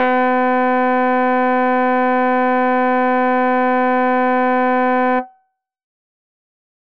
NEW MAGIC WAND Rhodes.wav